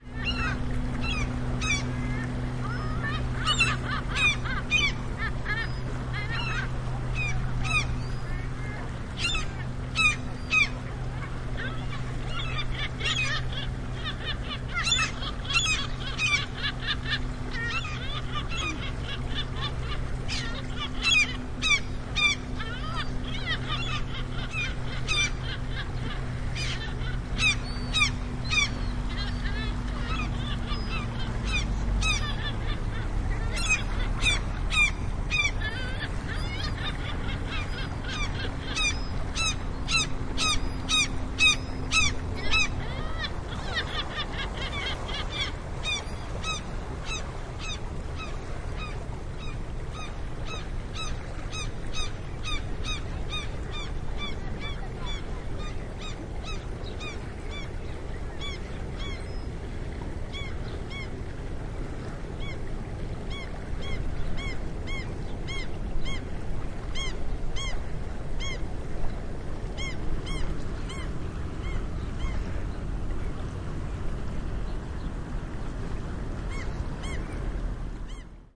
Call of Seagulls.mp3